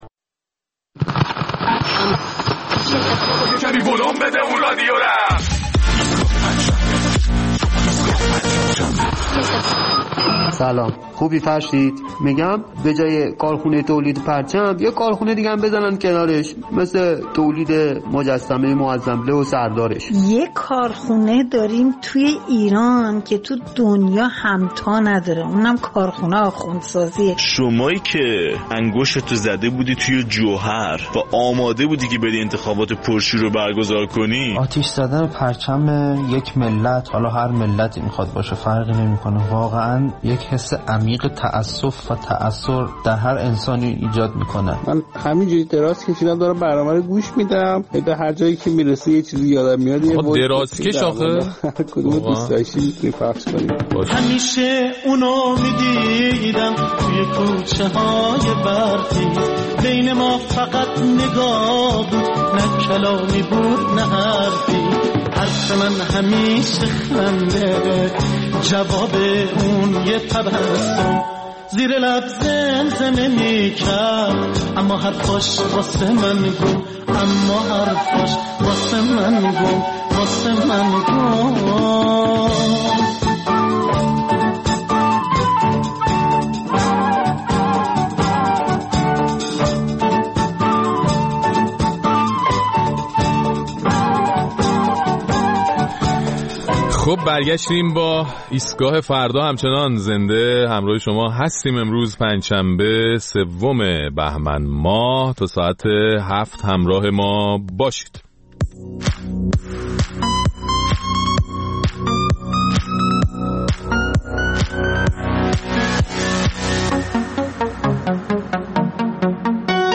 در این برنامه ادامه نظر شنوندگان ایستگاه فردا را در مورد تولید پرچم آمریکا در ایران و سوق دادن مردم برای راه رفتن روی آن می‌شنویم.